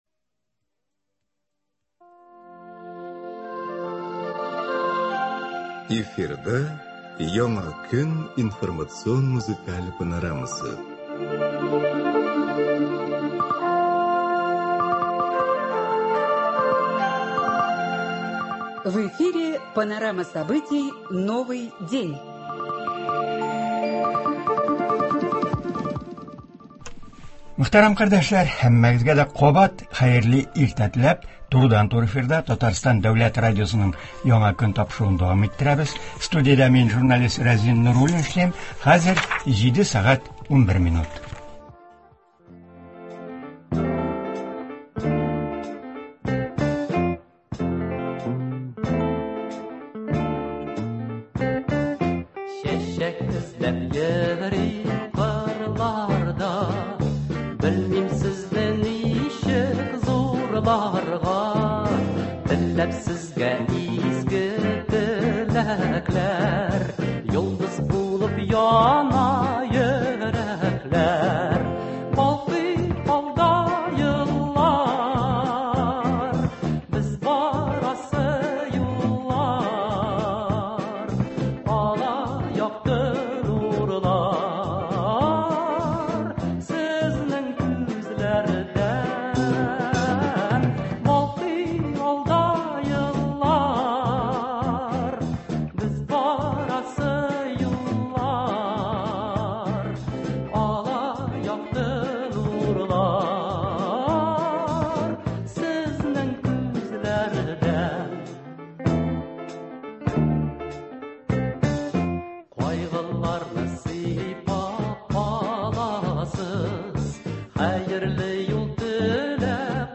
Алар 8 нче Март – Халыкара хатын-кызлар көнен нинди уңышлар белән каршылый? Татарстан республикасы Дәүләт Советының “Мәрхәмәт” хатын-кызлар төркеме нинди эшчәнлек алып бара? Болар хакында турыдан-туры эфирда Татарстан республикасы Дәүләт Советы депутаты, Мәскәү кооперация универитеты ректоры, профессор Алсу Рөстәм кызы Нәбиева сөйләячәк һәм тыңлаучылар сорауларына җавап бирәчәк.